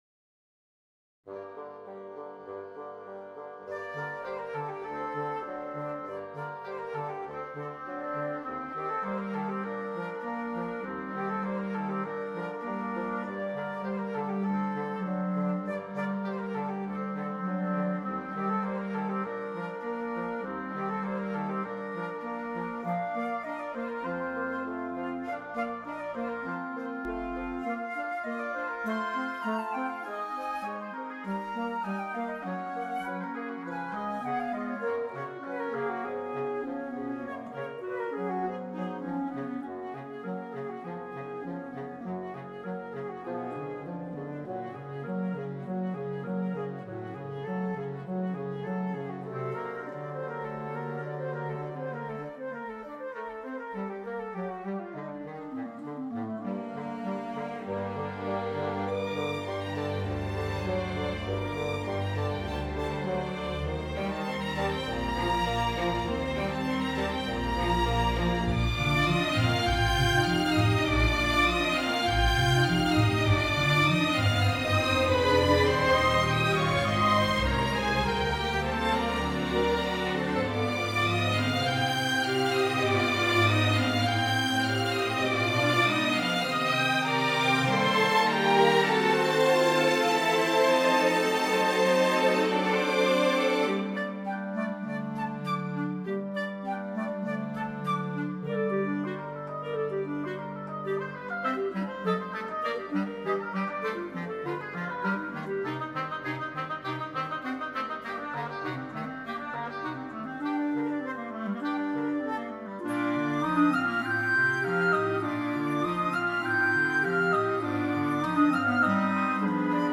This is my latest orchestration, and it's been my most challenging by far!
I'm guessing the flutes and oboes would struggle to play this?